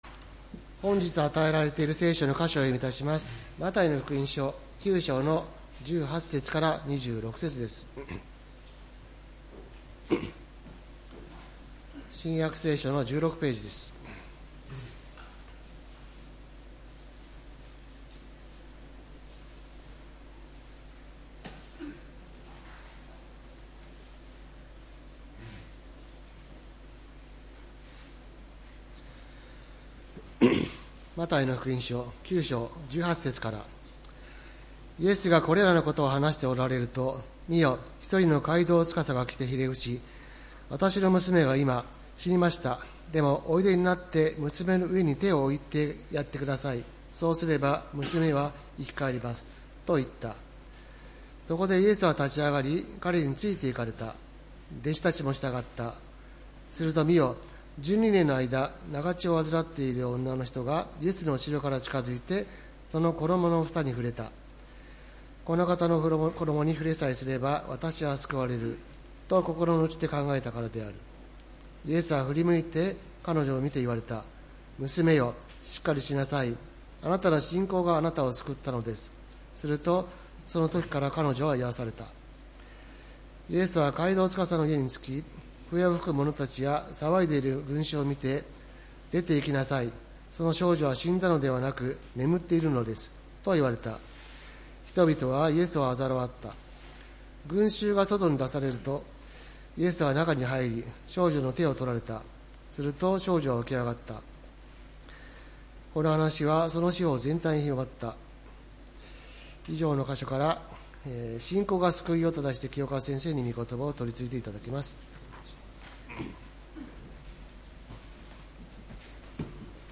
礼拝メッセージ「信仰が救いを」（12月７日）